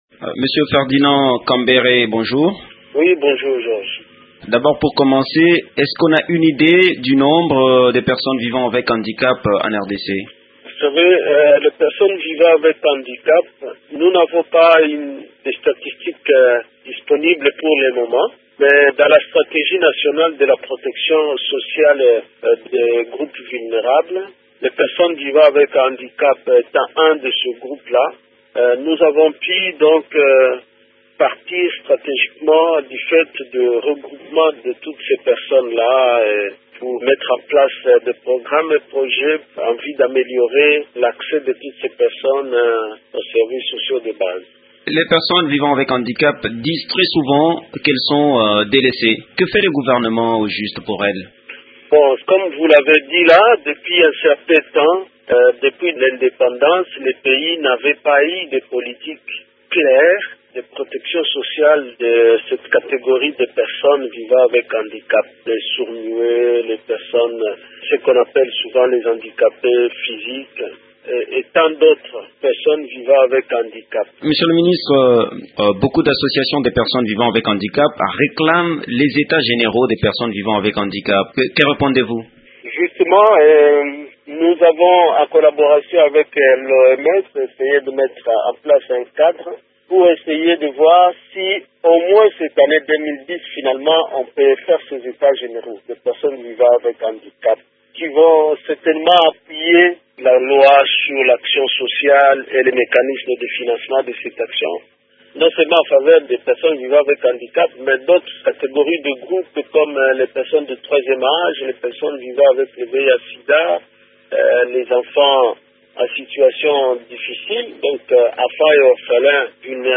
Le ministre des Affaires sociales et actions humanitaires répond à cette question.